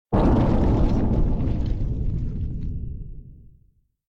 WallDecay1.ogg